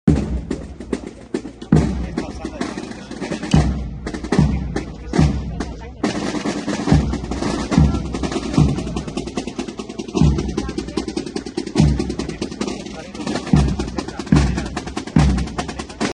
Comienzan a sonar los tambores y las marchas de procesión en Toledo y, con ellas, los ensayos para la Semana Santa.
A las puertas de la delegación de la ONCE en Castilla-La Mancha, cofrades ciegos y con discapacidad visual se han reunido estos días para ensayar el porteo y ser, un año más, costaleros en la Semana Santa toledana, de interés turístico internacional.
Así suenan los ensayos